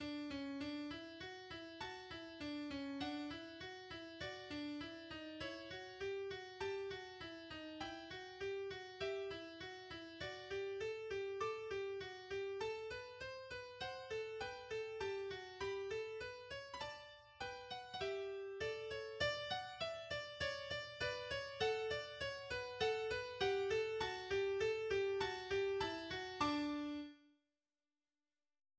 en ré majeur
Genre Symphonie
Effectif Orchestre classique possédant néanmoins des clarinettes
Le premier mouvement s'ouvre par quatre accords aux cordes.